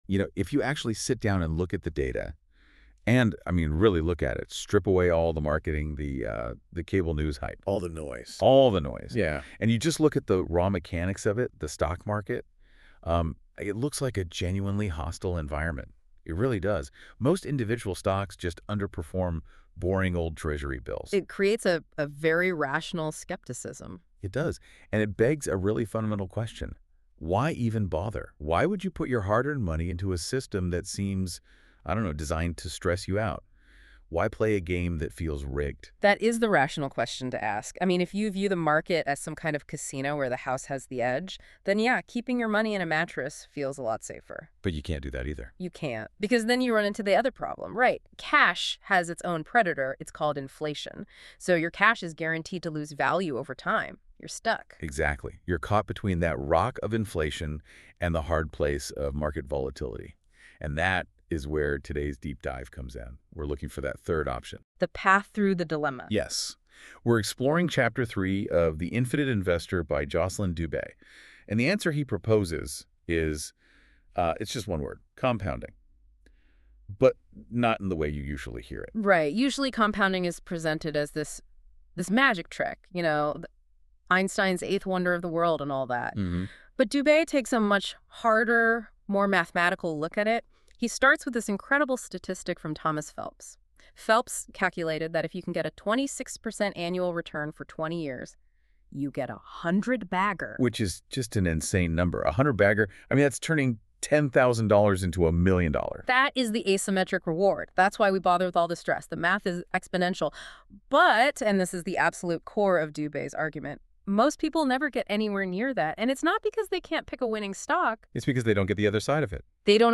🎧 Listen to the deep-dive discussion – The Barbell Strategy Prevents Total Ruin (18:14 min)